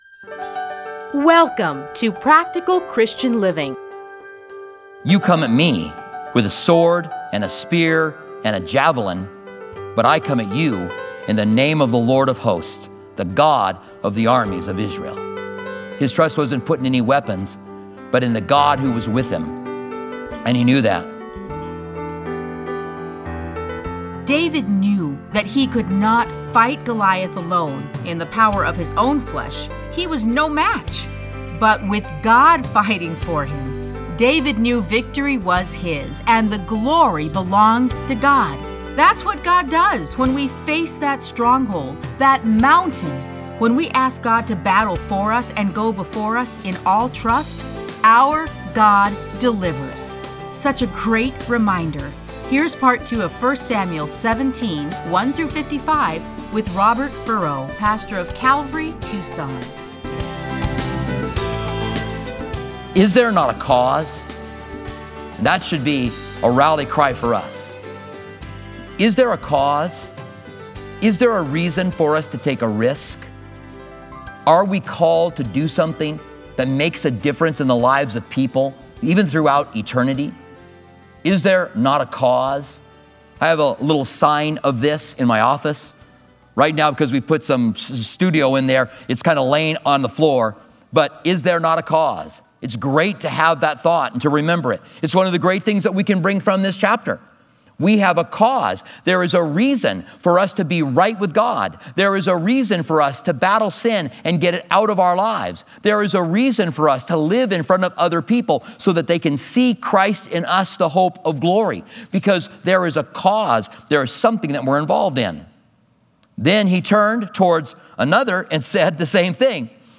Listen to a teaching from 1 Samuel 17:1-55.